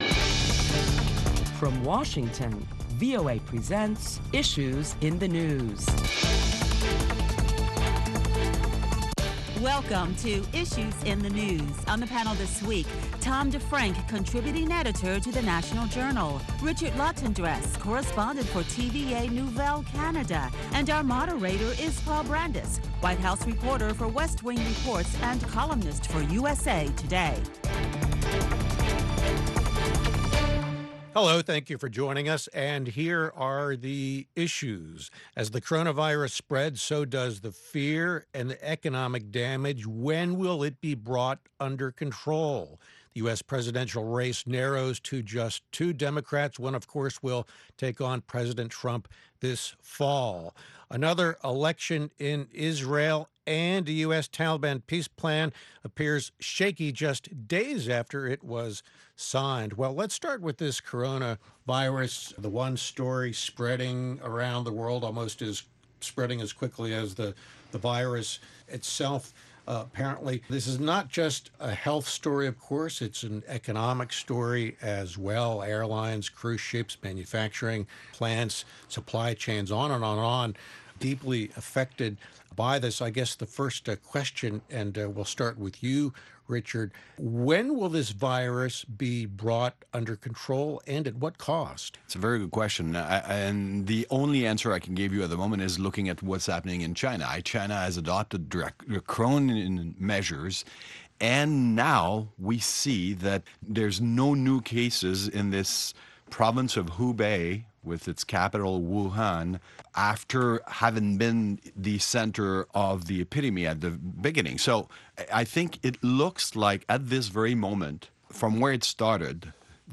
Listen to a panel of prominent Washington journalists as they deliberate the latest top stories of the week which will include 75 countries are reporting cases of the coronavirus.